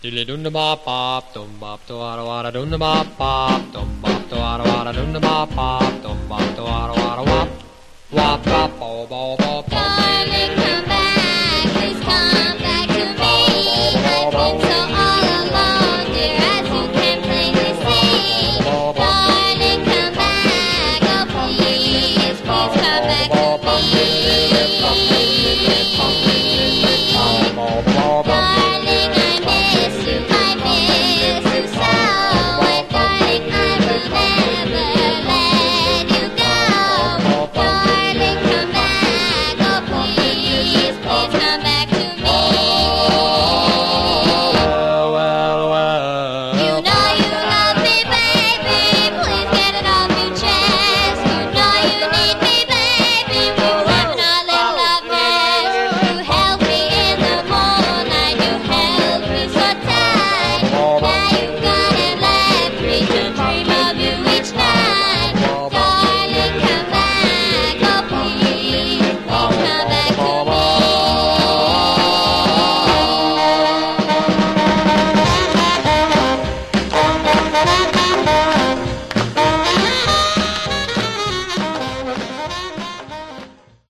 Genre: Vocal Groups (Doo-Wop)